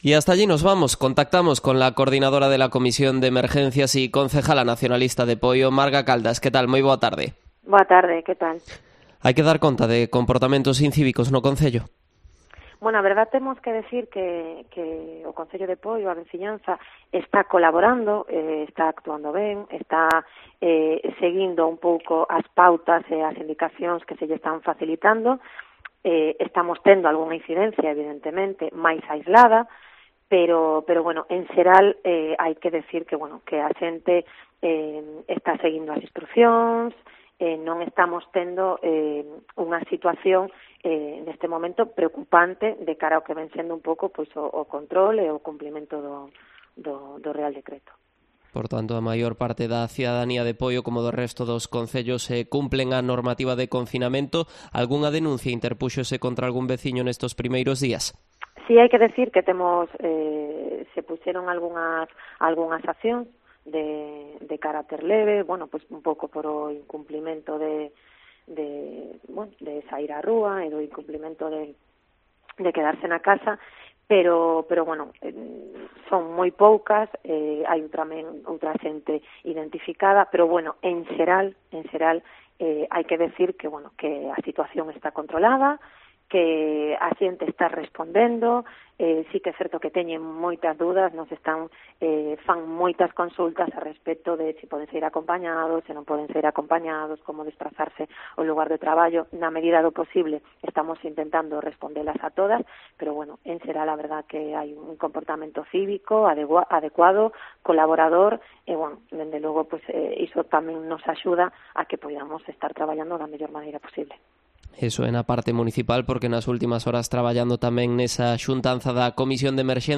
Entrevista a Marga Caldas, concejala al frente de la Comisión de Emergencia de Poio